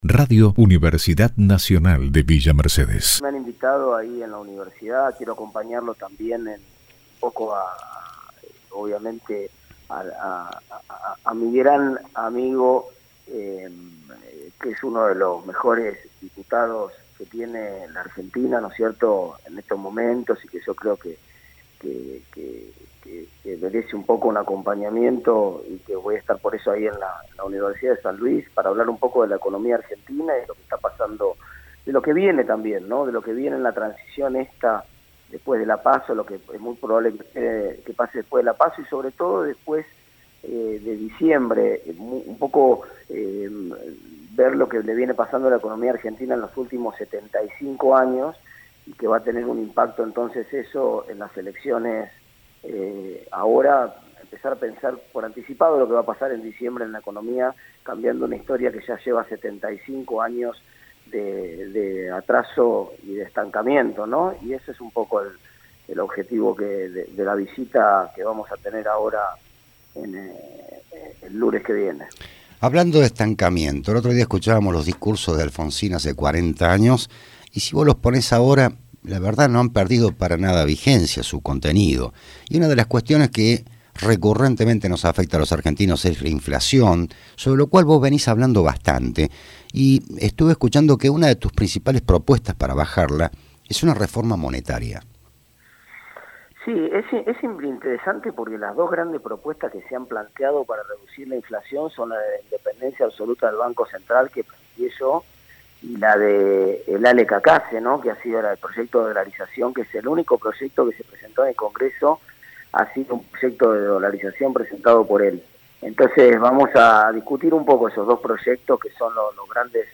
El dirigente concedió una entrevista a Radio UNViMe 93.7 MHz, durante la cual habló de la temática que abordará el próximo lunes y se refirió a otros temas de la actualidad política y económica.